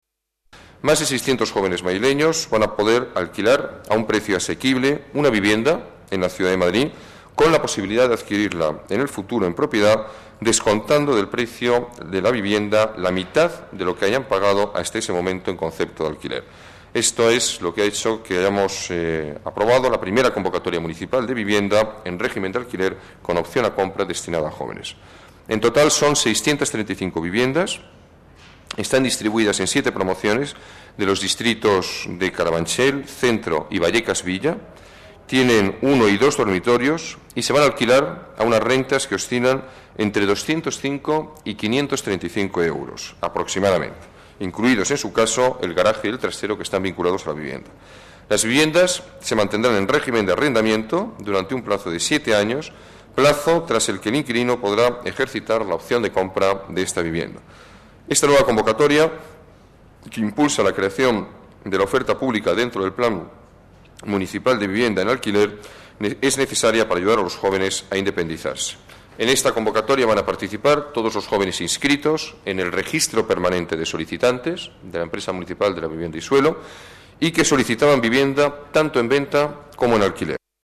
Declaraciones del alcalde de Madrid sobre las más de 600 viviendas para jóvenes en régimen de alquiler y con opción a compra El alcalde de Madrid ofrece datos sobre el Plan Integral de Vivienda y Suelo 2003-2008